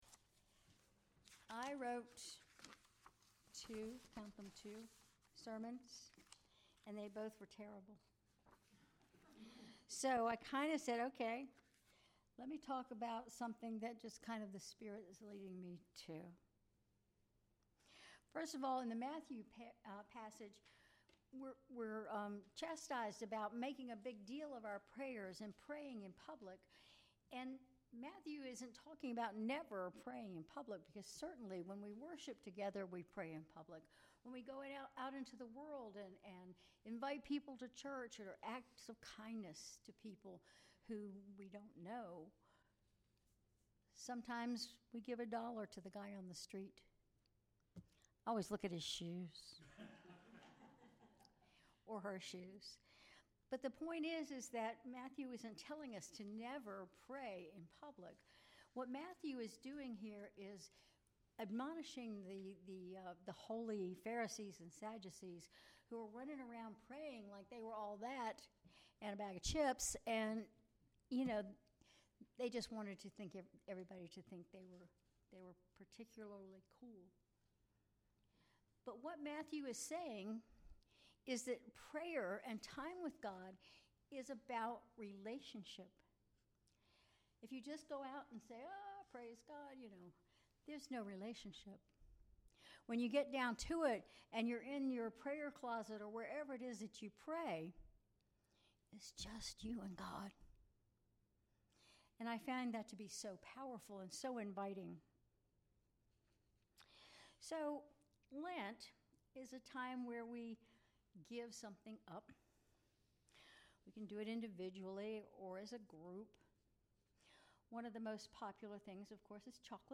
Sermon March 5, 2025 (Ash Wednesday)
Sermon_March_5_2025_Ash_Wednesday.mp3